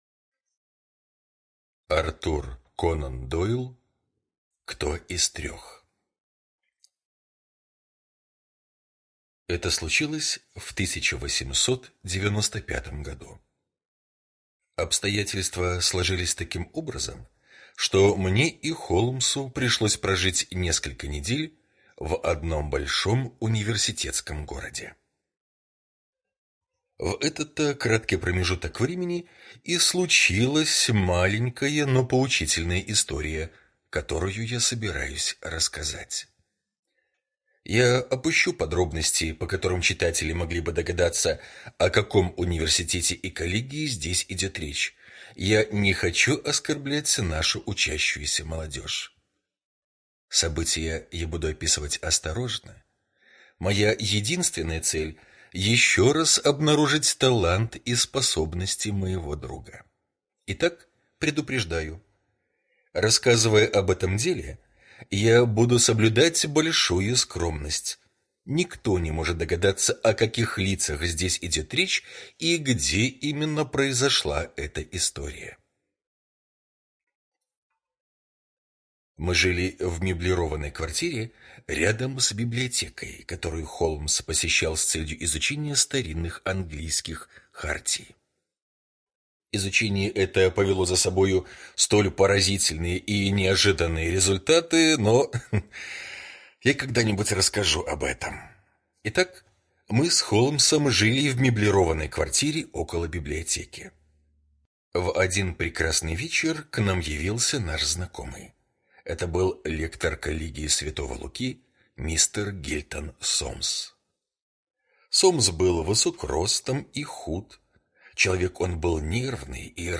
Кто из трех — слушать аудиосказку Артур Конан Дойл бесплатно онлайн